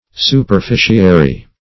Superficiary \Su`per*fi"ci*a*ry\, a.